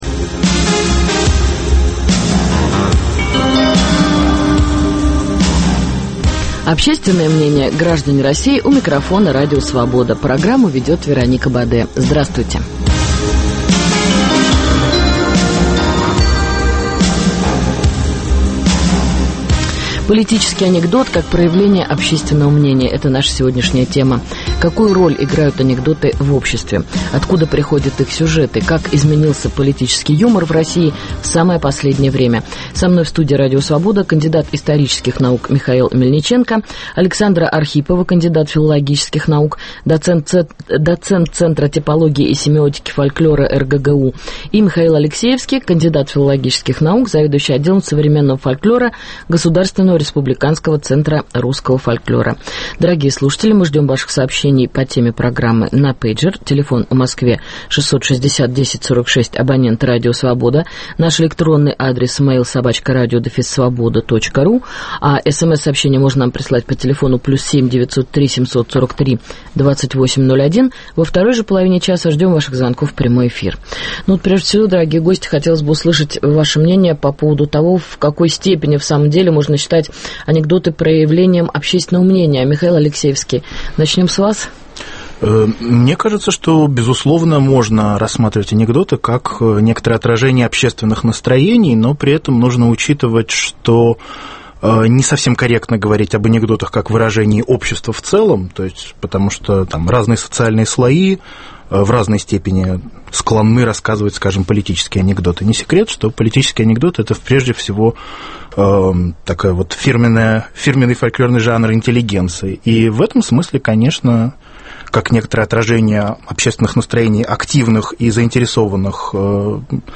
Как изменился политический фольклор в период оживления протестного движения в России? Участники программы – историк